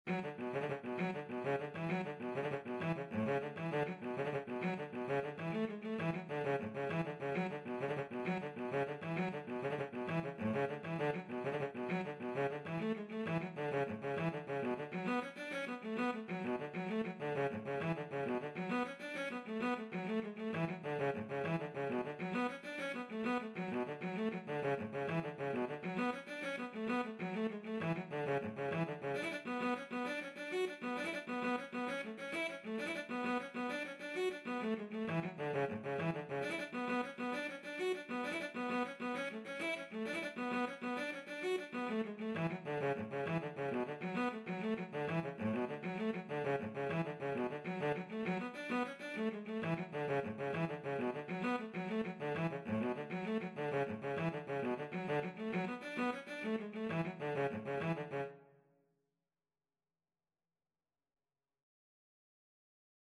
B minor (Sounding Pitch) (View more B minor Music for Cello )
6/8 (View more 6/8 Music)
Cello  (View more Intermediate Cello Music)
Traditional (View more Traditional Cello Music)
Irish